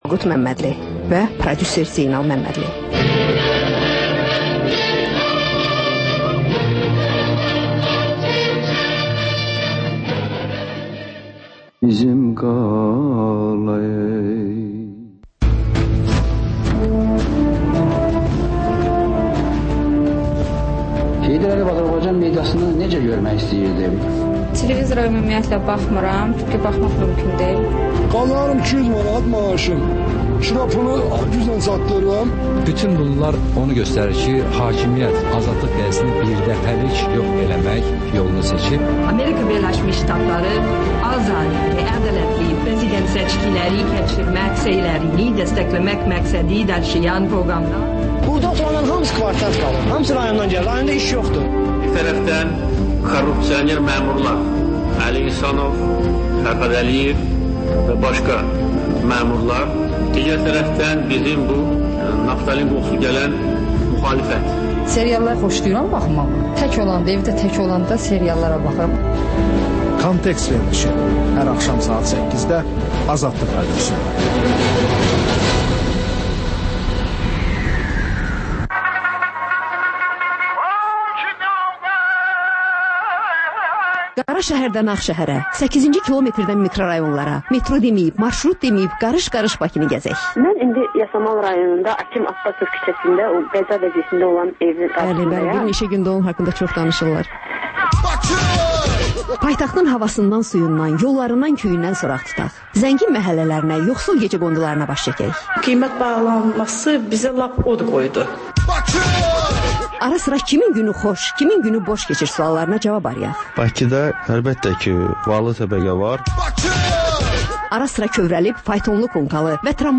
Ölkənin tanınmış simaları ilə söhbət